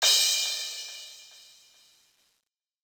(MURDA) CRASH.wav